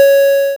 snd_chem_countdown.wav